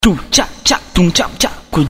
uma música